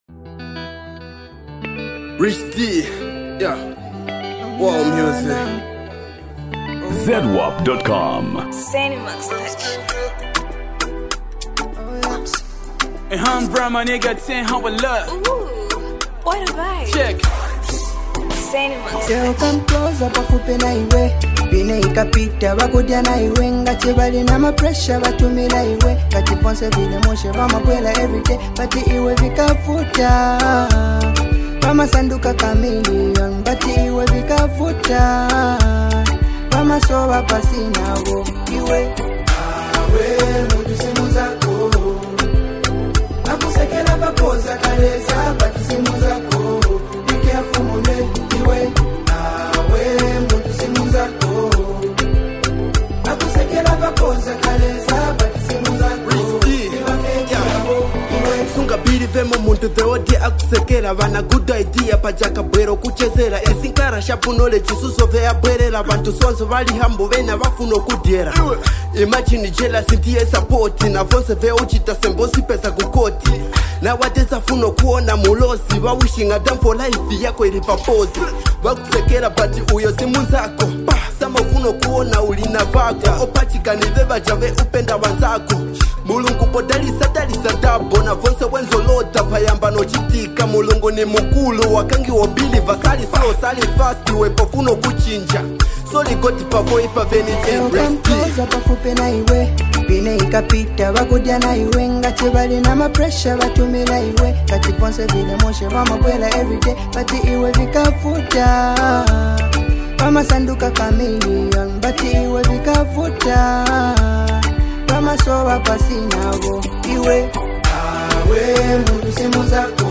Género musical: Afro Beat